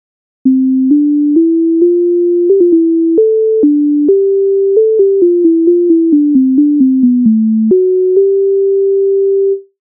MIDI файл завантажено в тональності C-dur